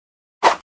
swordslash.mp3